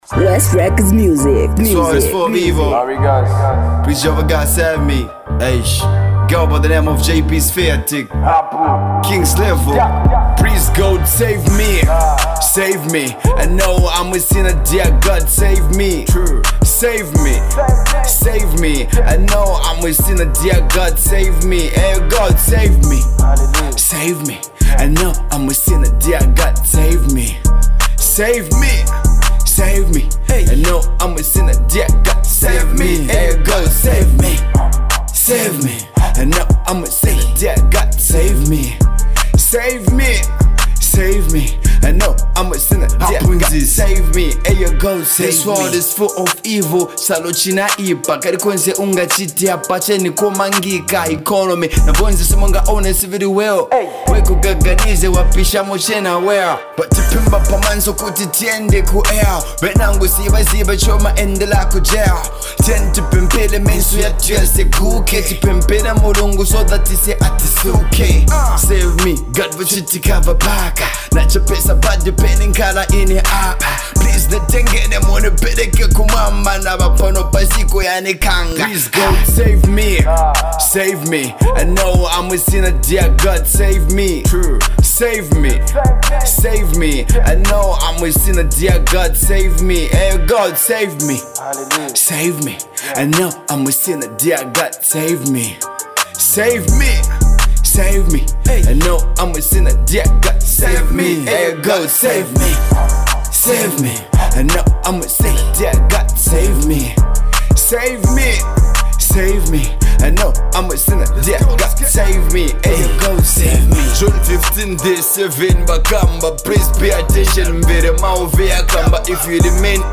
rap gospel